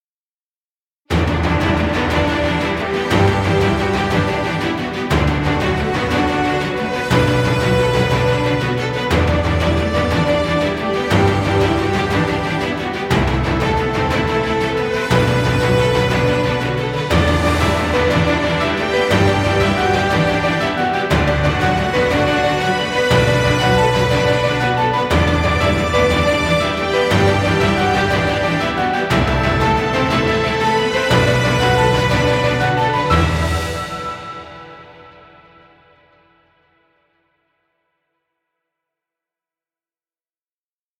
Epic dramatic music.